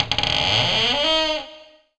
door4_close.wav